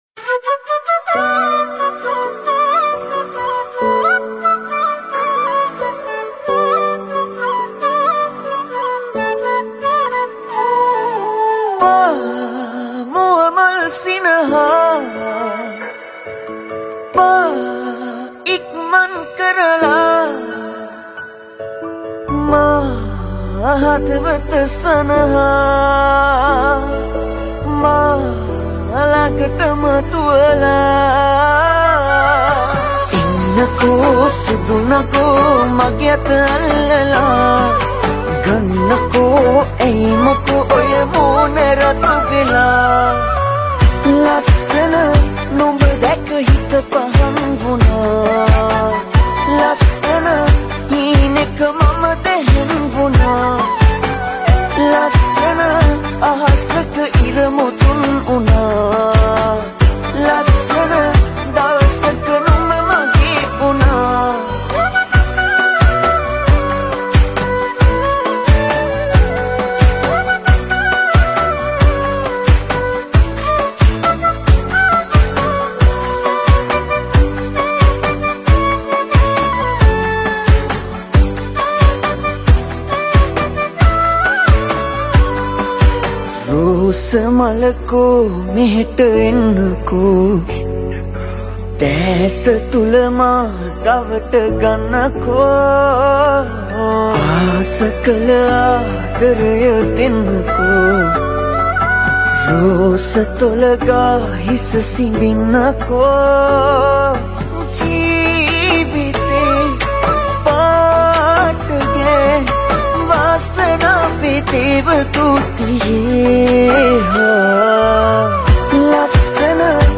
High quality Sri Lankan remix MP3 (3.8).